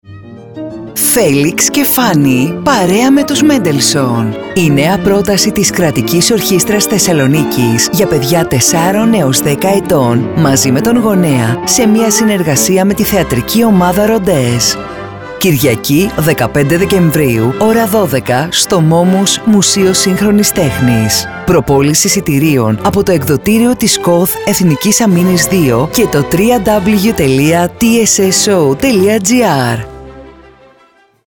Κατηγορία: Ραδιοφωνικά σποτ